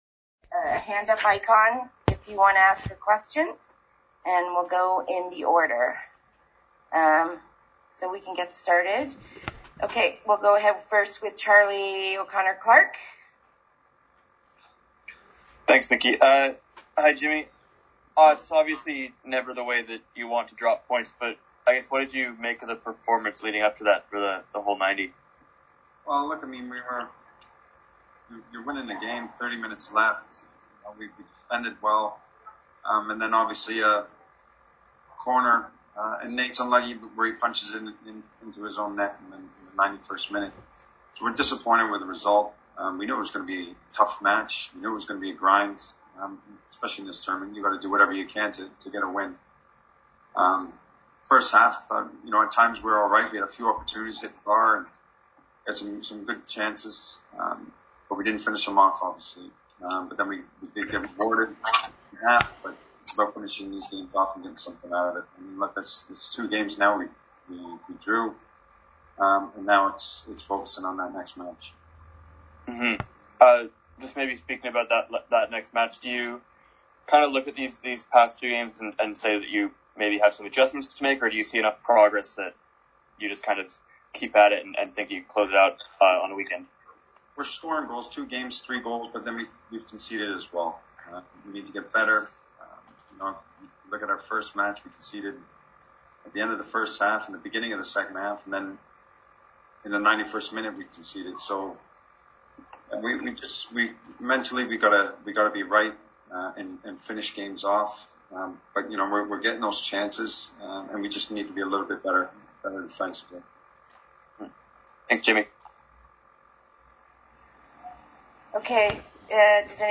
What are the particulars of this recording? Post game press conference conducted via Zoom.